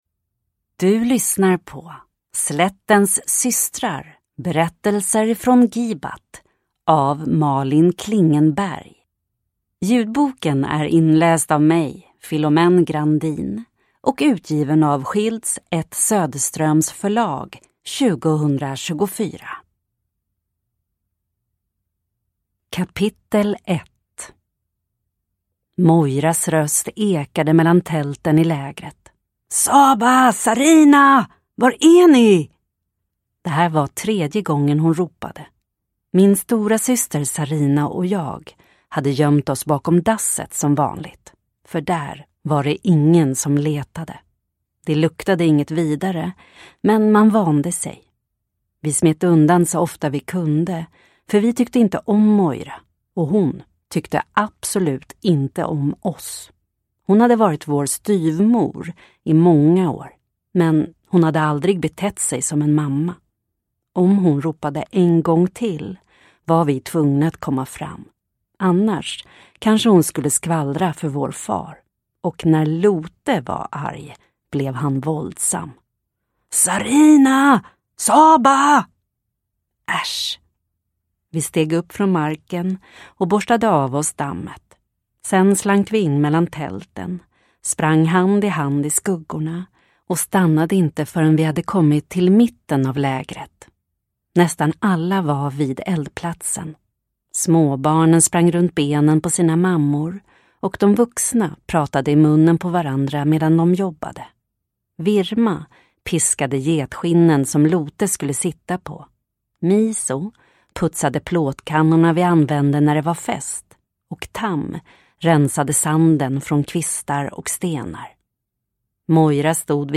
Slättens systrar – Ljudbok